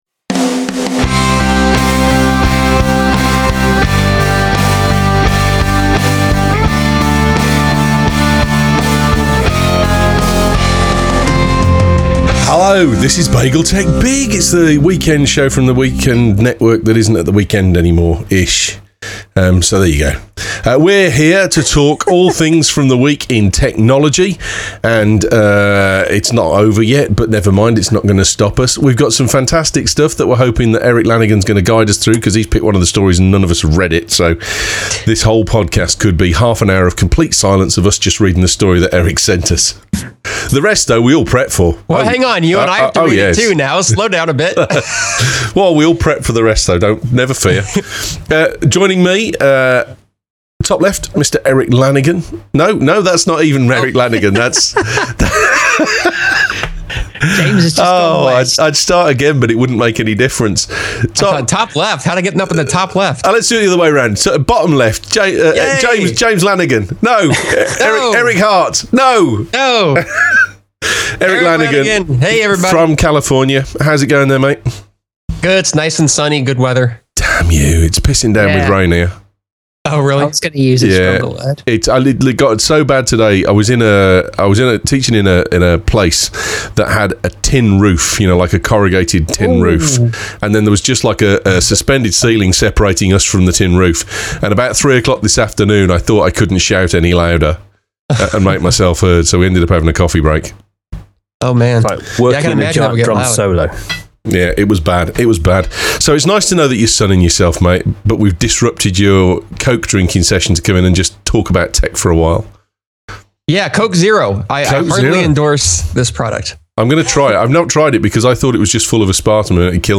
Why are SSDs so damn expensive? Lively and silly debate teases out a few reasons. British Copyright Law is under scrutiny this week and finally what the Google Patent beef thing all about anyway?